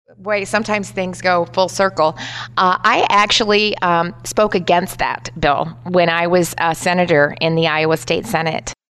Reynolds made her comments during an interview with Radio Iowa.